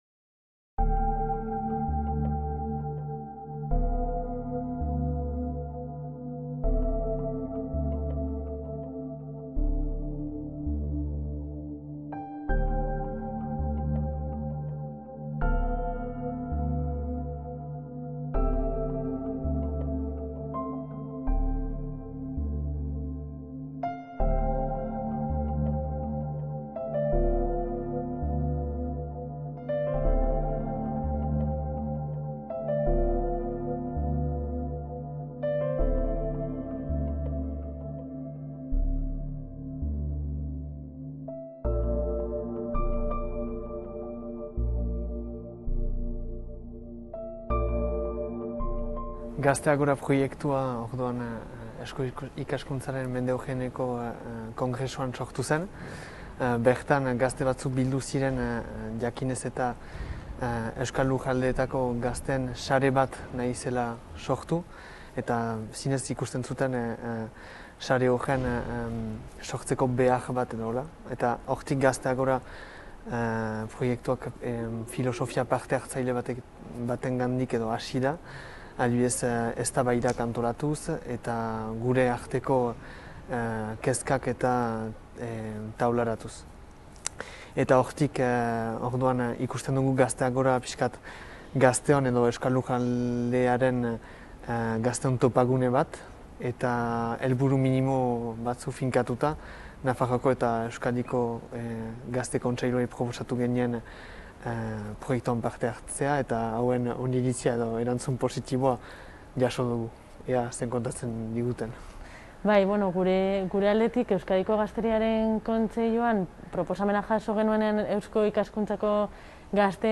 Elkarrizketan, Gazte Agora mugaz gaindiko lan-programaren motibazioei eta garrantziari, euskal gazteen kezkak eta gabeziak atzemateko egin duen ekarpenari eta hurrengo hilabeteetan lan horrekin jarraitzeko planei buruz hitz egiten dute.